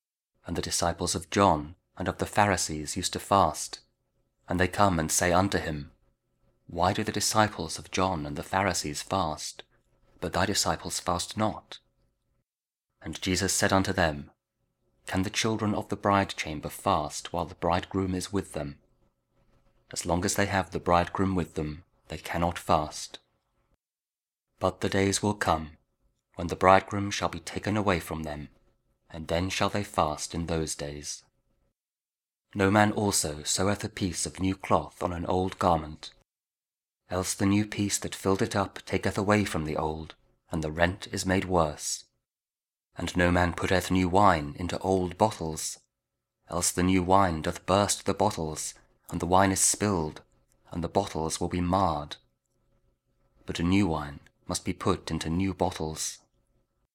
Audio Bible Verses | Jesus | New Wine , Old Bottles | Christ The Bridegroom